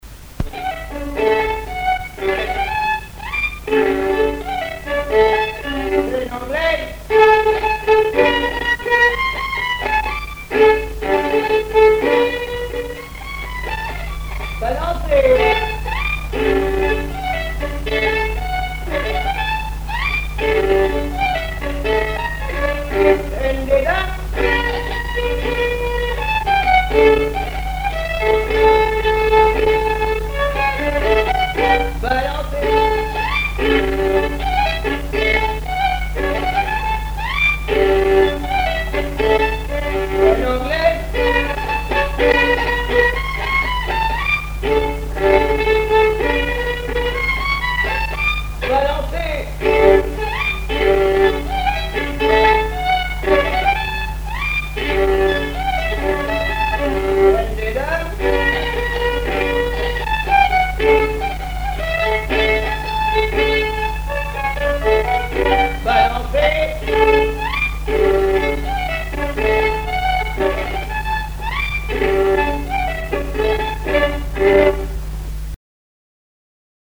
Mémoires et Patrimoines vivants - RaddO est une base de données d'archives iconographiques et sonores.
danse : quadrille : chaîne anglaise
Répertoire de violoneux
Pièce musicale inédite